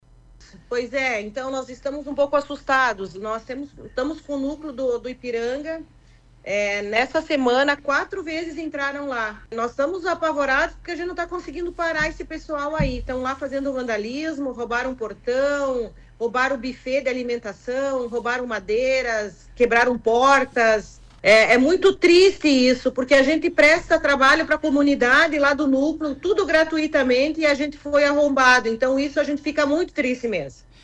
Em entrevista à Rádio Planalto News (92.1)